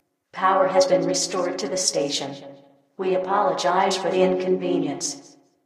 Paradise/sound/AI/poweron.ogg
poweron.ogg